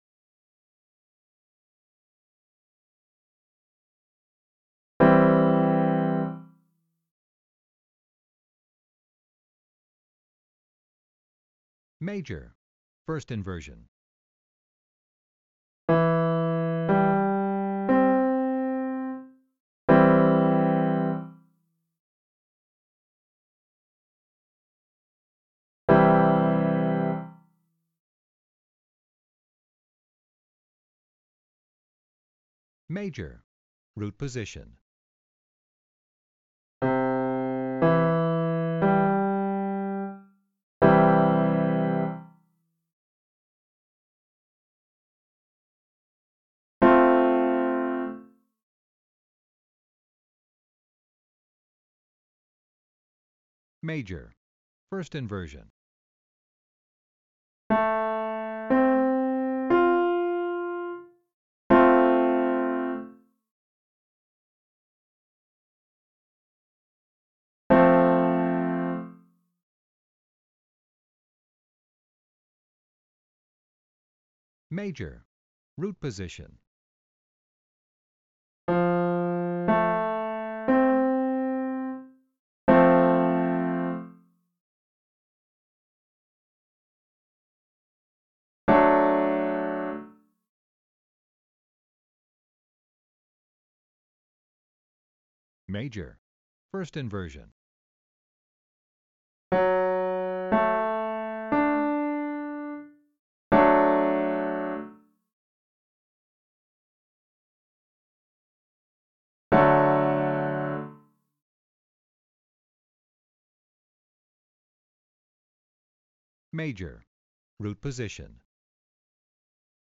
Once you think you’re getting a sense of each inversion’s sound, listen to the corresponding “Test” tracks, which include a short pause after each chord.
You’ll hear the correct answer so you know if you got it right and have the chance to hear the chord again.
Test_1._Major_triad_inversions_root_and_first_inversion.mp3